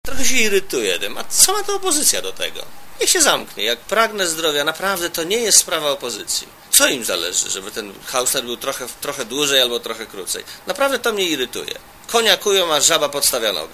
Premier Marek Belka potrzebuje jeszcze kilku dni, by ujawnić datę dymisji wicepremiera Jerzego Hausnera. Premier na konferencji prasowej w Madrycie dodał, że nazwisko następcy ujawni w momencie dymisji Hausnera.
Mówi premier Marek Belka